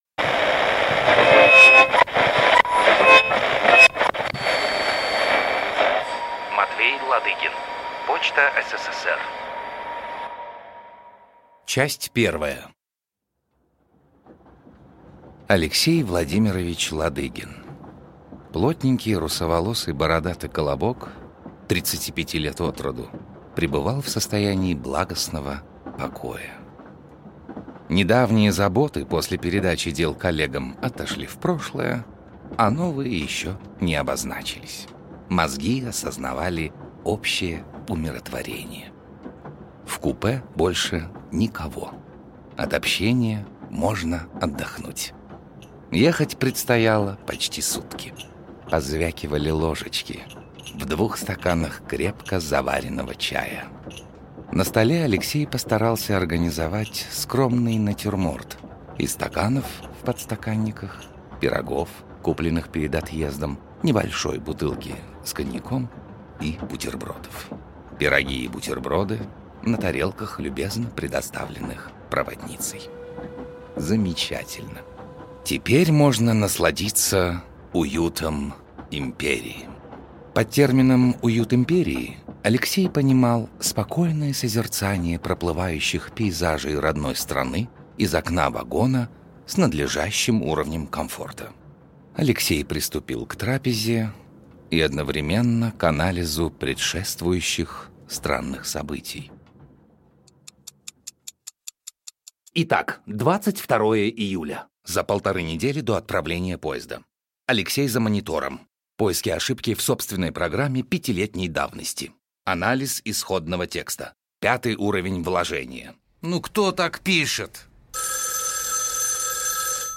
Аудиокнига Почта СССР | Библиотека аудиокниг
Прослушать и бесплатно скачать фрагмент аудиокниги